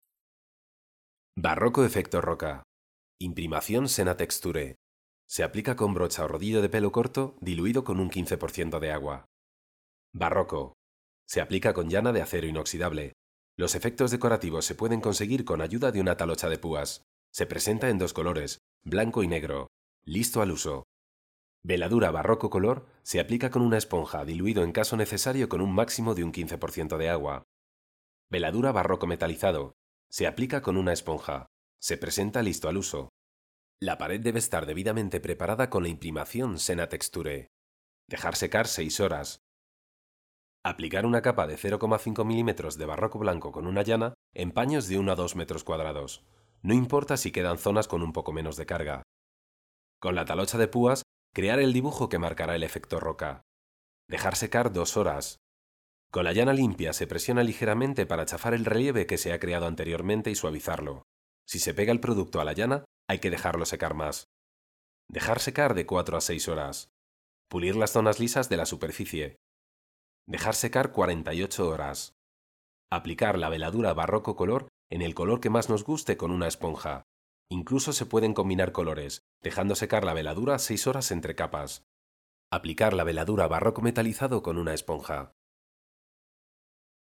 Spanish voice over artist. Will do any voice over in neutral Spanish
kastilisch
Sprechprobe: Industrie (Muttersprache):
Clear middle aged voice for any audiovisual project.